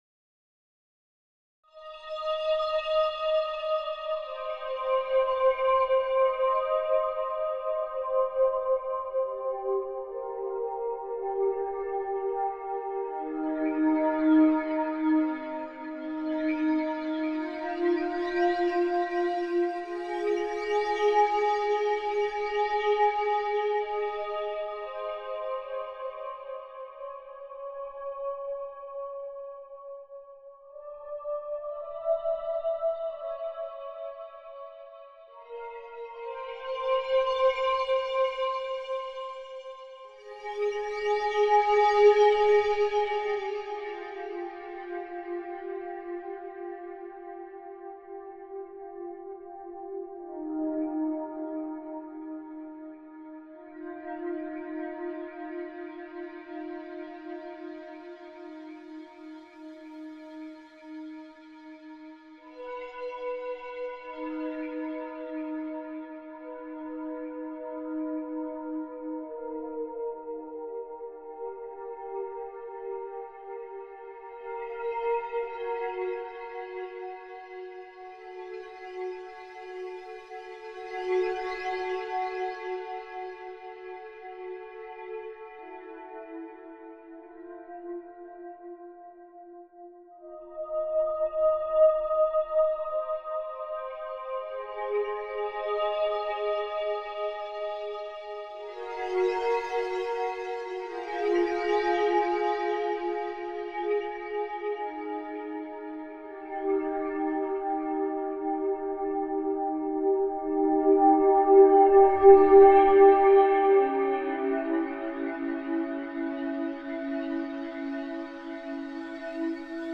TEDIO – Musica per abitare il silenzio Non cercare una melodia: lascia che il suono ti avvolga come un respiro lento. La sua calma ripetitiva sospende il tempo e apre uno spazio dove i pensieri si acquietano.
Struttura : il brano sembra mantenere un pattern ritmico e timbrico piuttosto costante, con piccole variazioni progressive. Timbro : prevalenza di frequenze medio-basse con armonici costanti, che creano una base stabile e ipnotica. Andamento : non ci sono “esplosioni” o rotture improvvise; piuttosto una lenta evoluzione, quasi come un paesaggio sonoro statico ma vivo. Effetto uditivo : ricorda certe composizioni minimaliste o ambient, dove la ripetizione e la costanza diventano parte integrante della suggestione.
Il ritmo costante diventa quasi un respiro regolare, che aiuta a lasciar andare i pensieri frenetici.